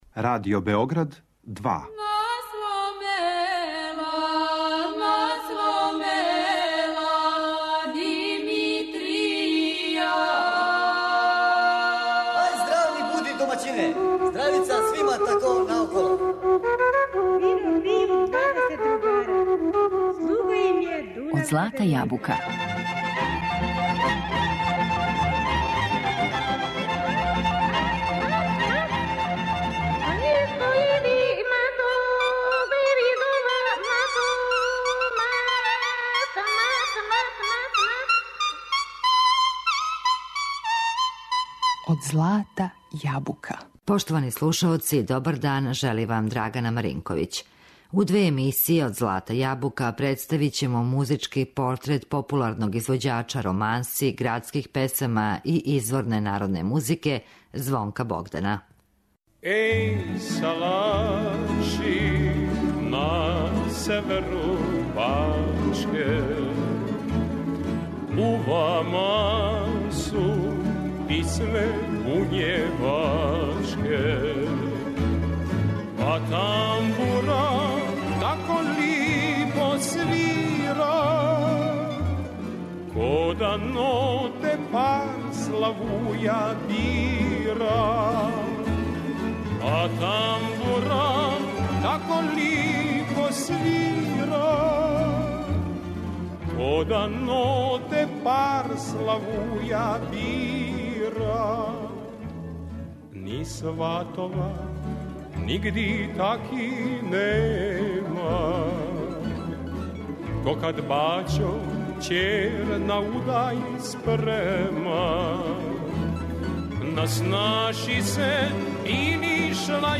За његов глас веже се танана војвођанска песма, као и добра романса, градска песма и изворна народна.